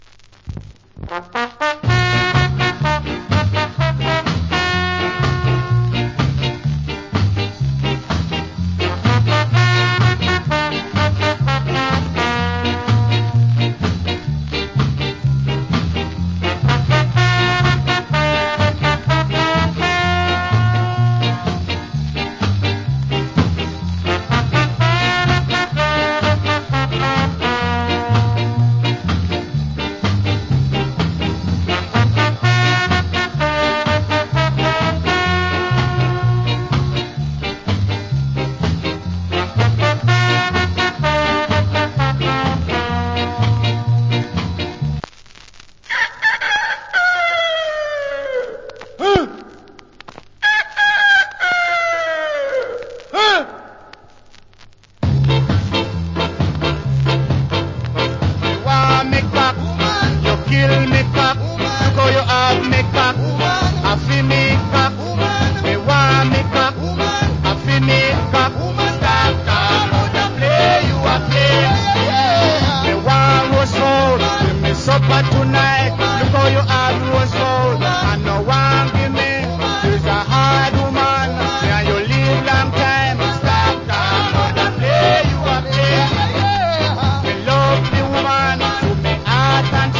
Authentic Ska Inst.